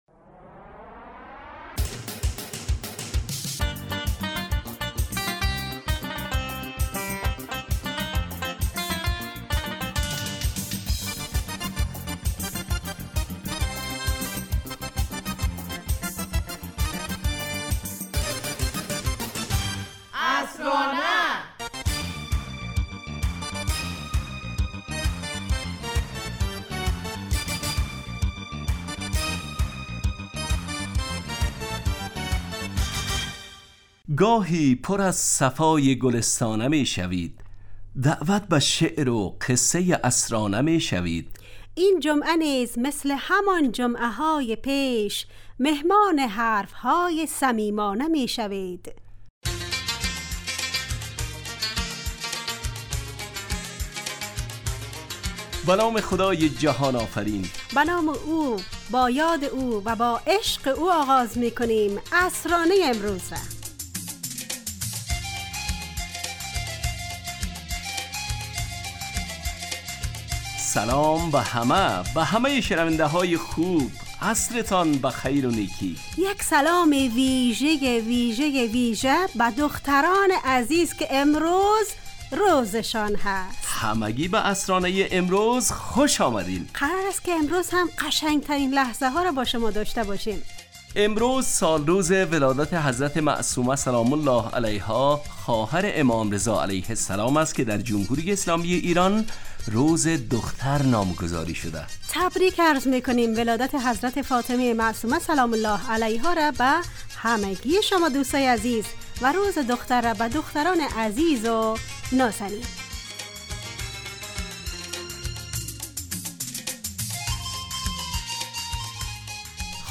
عصرانه برنامه ایست ترکیبی نمایشی که عصرهای جمعه بمدت 35 دقیقه در ساعت 17:55 دقیقه به وقت افغانستان پخش می شود و هرهفته به یکی از موضوعات اجتماعی و فرهنگی...